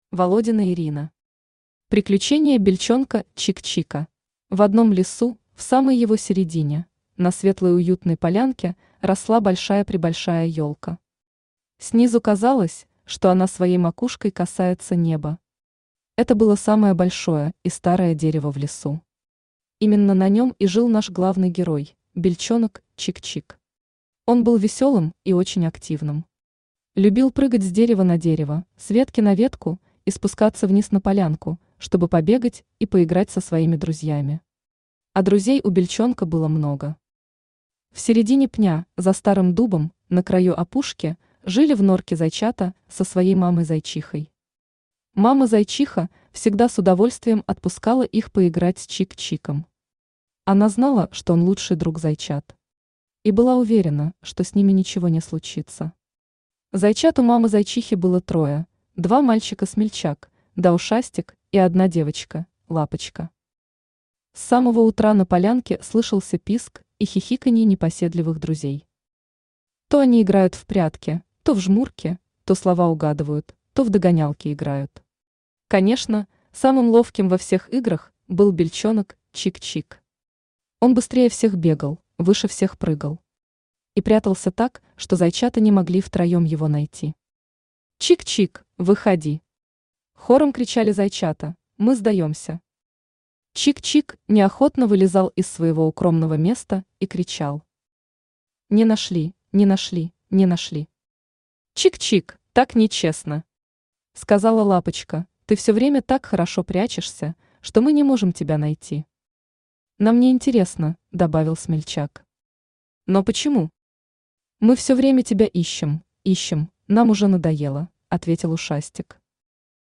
Аудиокнига Приключения бельчонка Чик-Чика | Библиотека аудиокниг